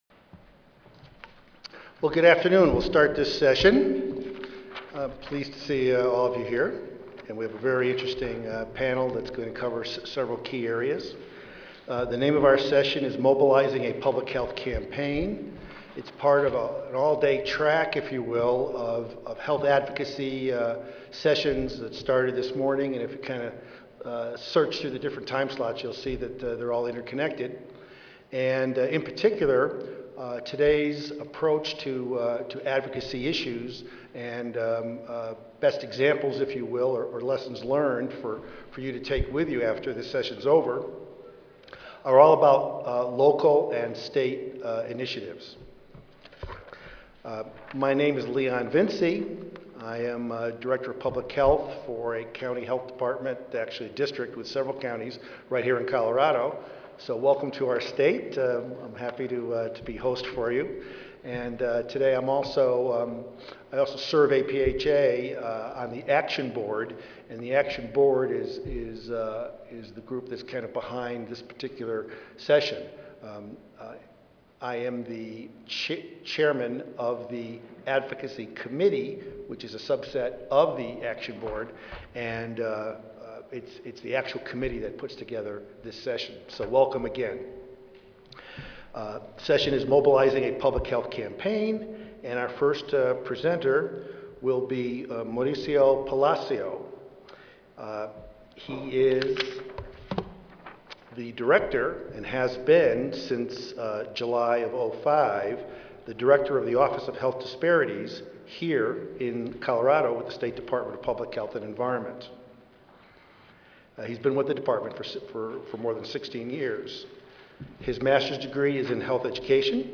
3290.0 Mobilizing a Public Health Campaign Monday, November 8, 2010: 2:30 PM - 4:00 PM Oral Learn how to coordinate an effective grassroots campaign in support of a public health issue! This session will give participants the basic tools needed to implement a grassroots campaign on the federal, state or local levels. Participants will receive an overview of the different components to putting together a successful advocacy campaign, such as planning a strategy, developing a message, obtaining funding, and setting and achieving goals. Panelists also will share their perspectives on the best ways to engage the public, lawmakers, the media, and partners in support of a public health issue.